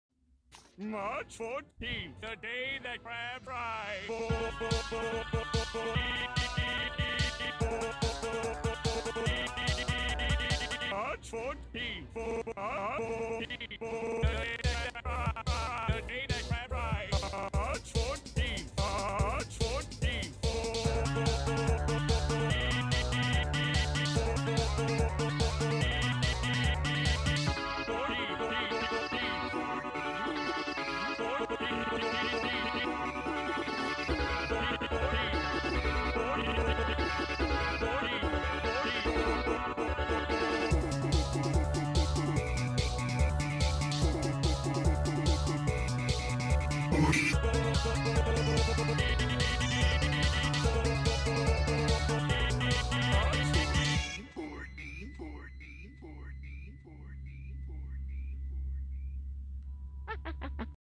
Sparta No BGM Remix